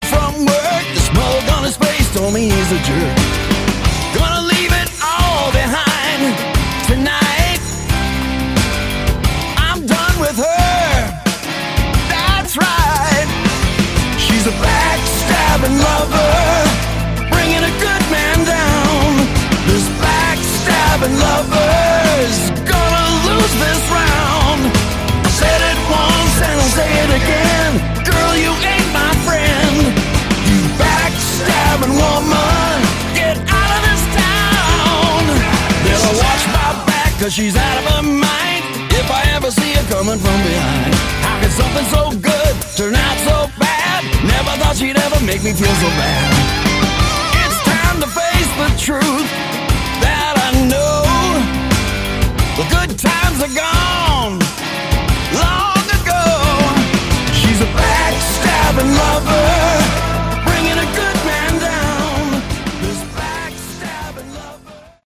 Category: Southern Rock
vocals, guitar
drums
bass